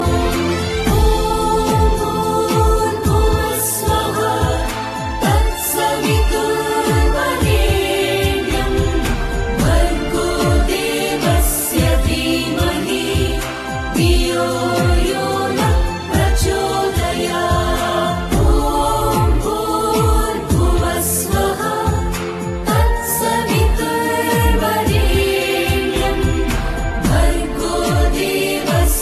File Type : Bhajan mp3 ringtones